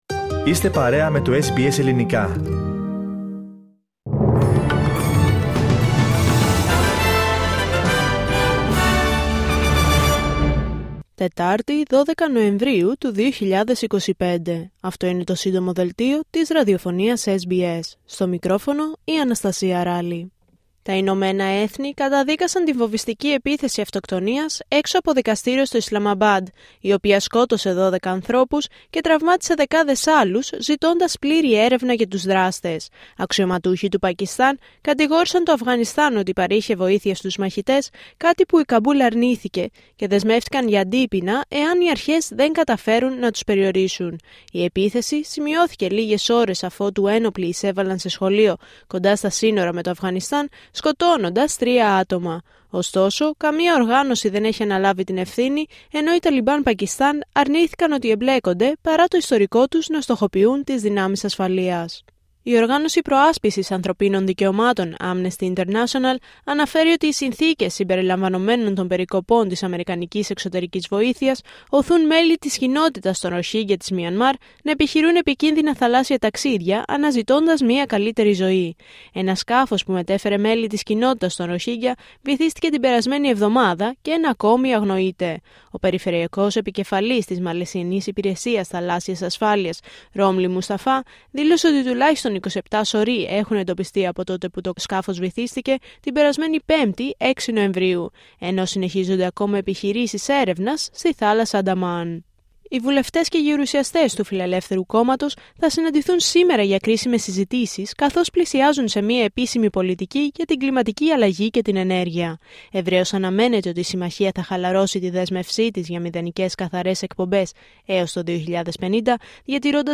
H επικαιρότητα έως αυτή την ώρα στην Αυστραλία, την Ελλάδα, την Κύπρο και τον κόσμο στο Σύντομο Δελτίο Ειδήσεων της Τετάρτης 12 Νοεμβρίου 2025.